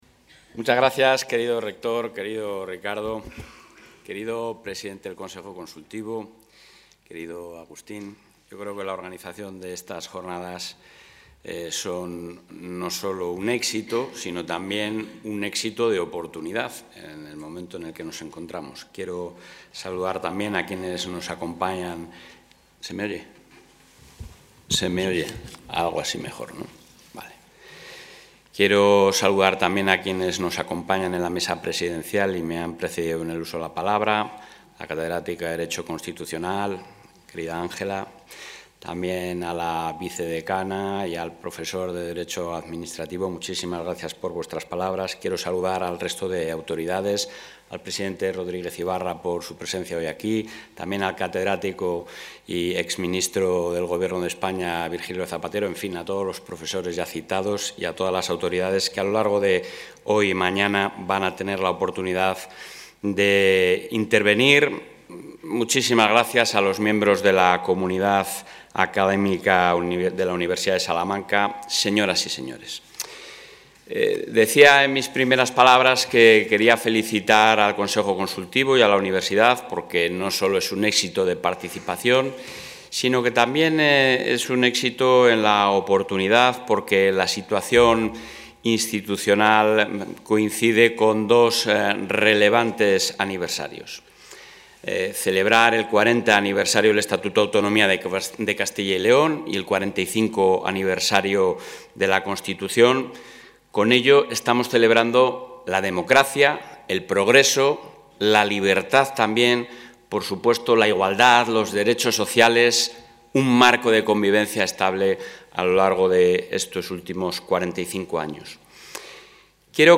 El presidente de la Junta de Castilla y León, Alfonso Fernández Mañueco, ha inaugurado hoy en Salamanca las jornadas...
Intervención del presidente.